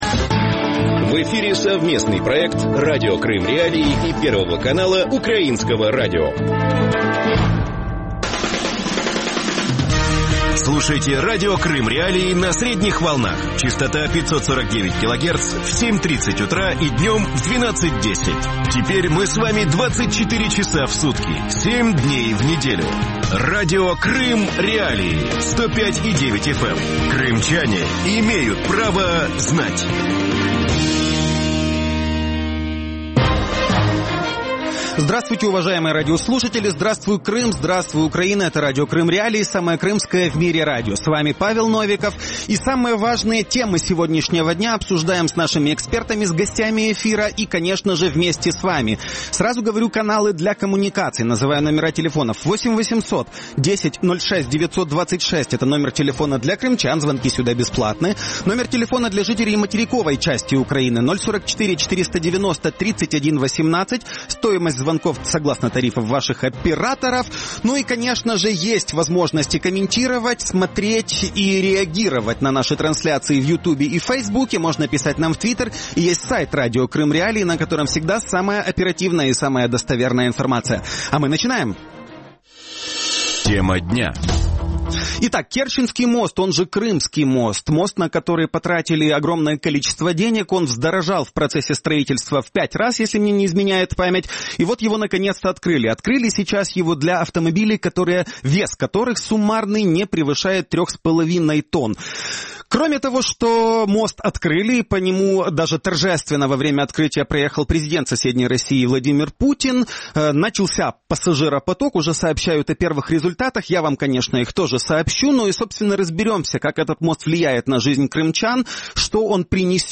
Гости эфира
крымский активист
крымский историк и политолог.